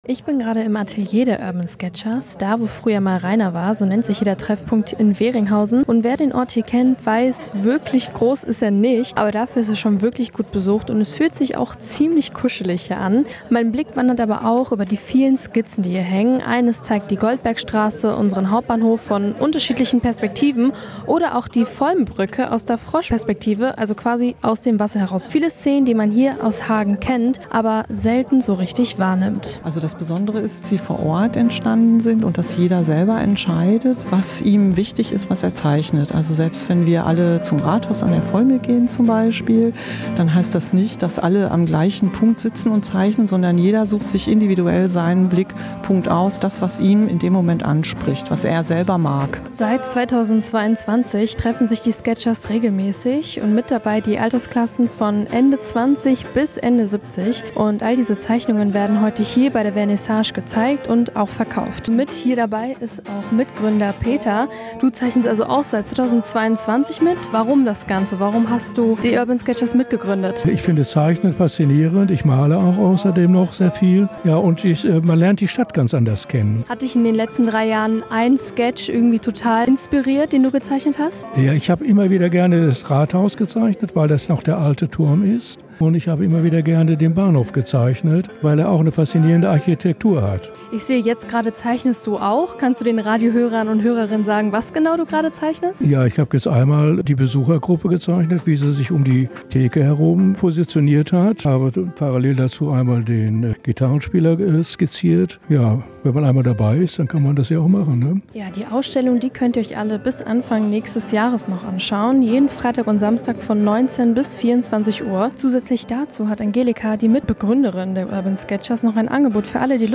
reportage-urban-sketchers.mp3